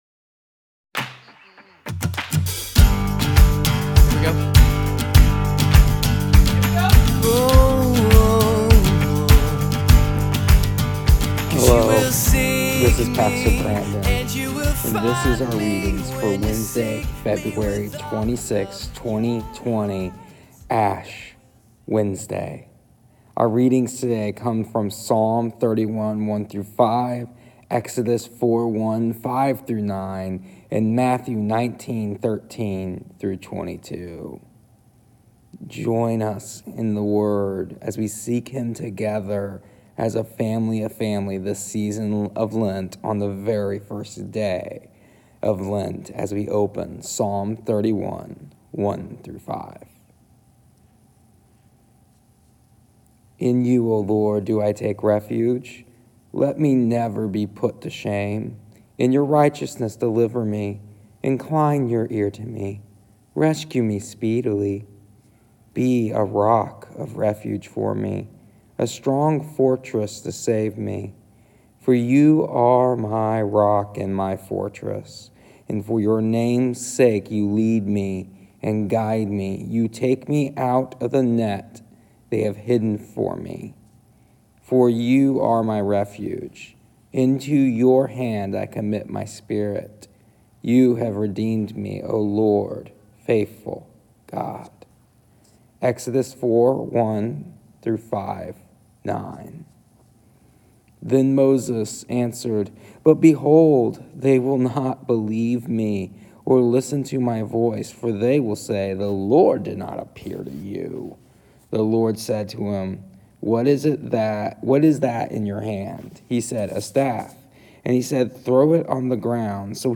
With this being said, here are our readings and audio devotional for today Ash Wednesday, the first day of Lent, February 26th, 2020.